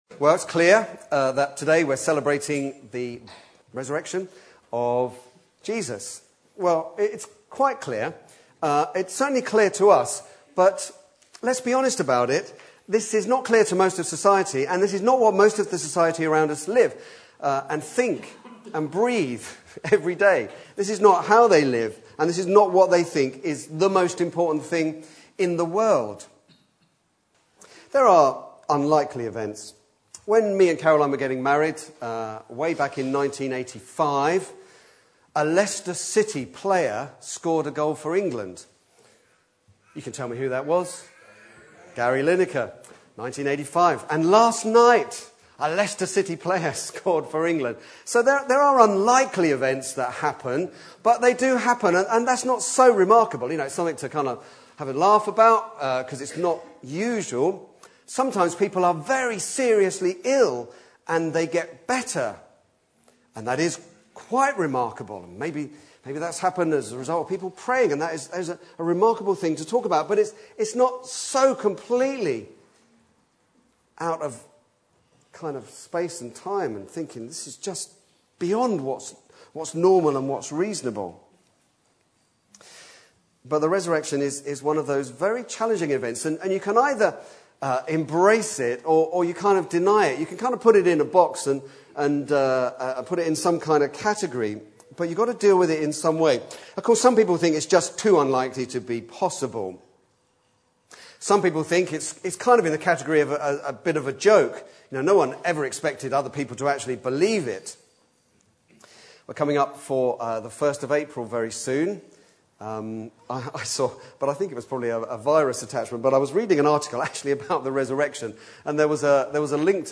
Back to Sermons He is risen